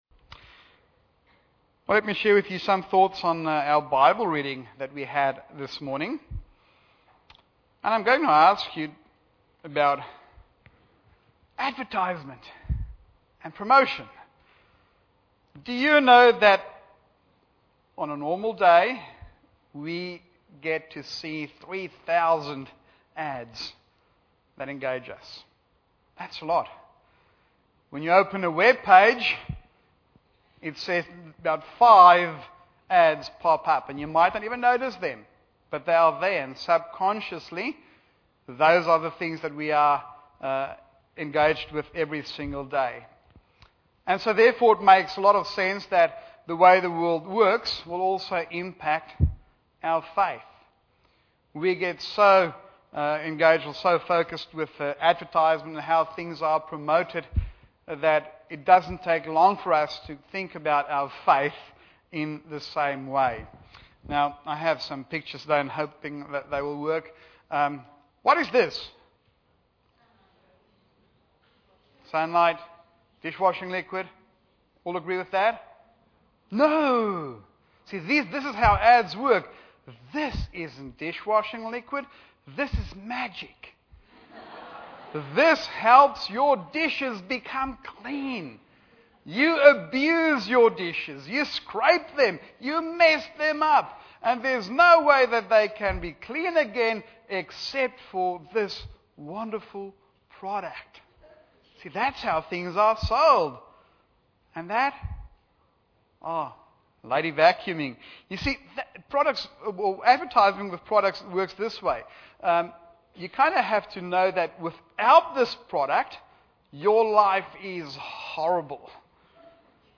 Combined Mid-Year Service 2017 – Grow